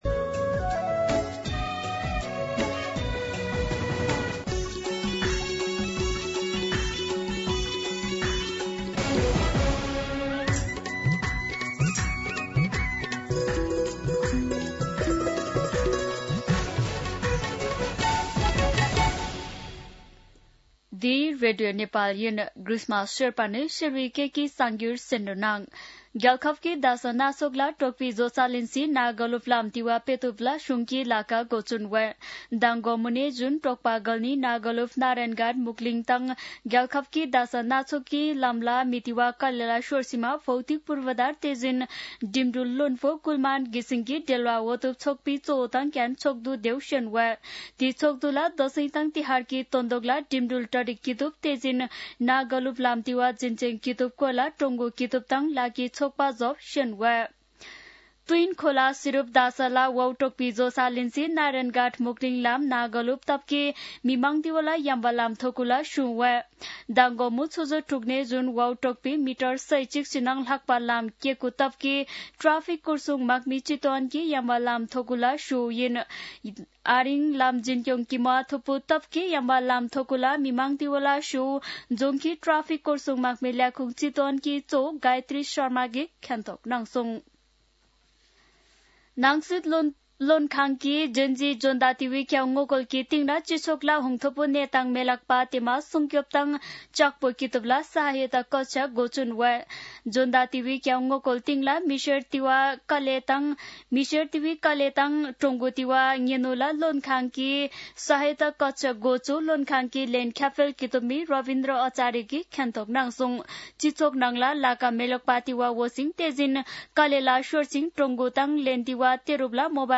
शेर्पा भाषाको समाचार : ४ असोज , २०८२
Sherpa-News-8.mp3